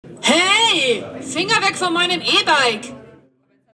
Wenn das abgeschlossene Rad bewegt wird, warnt eine laute Stimme potentielle Diebe.
Sprechendes Fahrrad